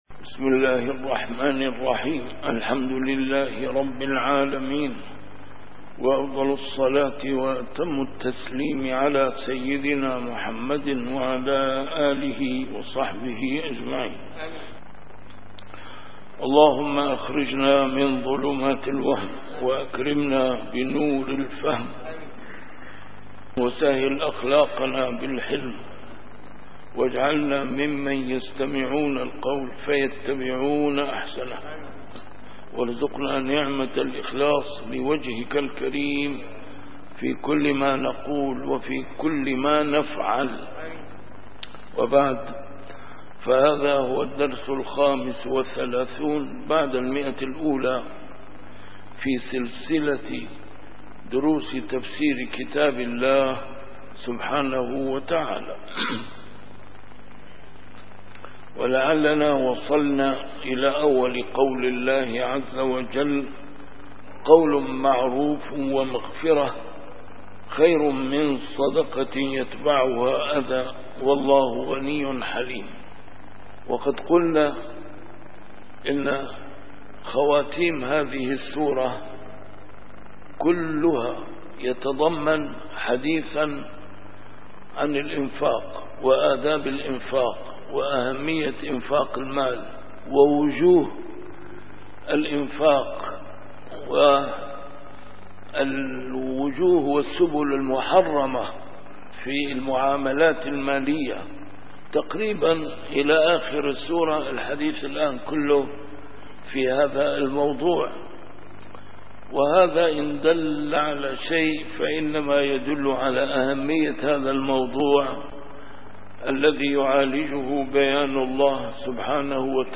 A MARTYR SCHOLAR: IMAM MUHAMMAD SAEED RAMADAN AL-BOUTI - الدروس العلمية - تفسير القرآن الكريم - تفسير القرآن الكريم / الدرس الخامس والثلاثون بعد المائة: سورة البقرة: الآية 263 - 265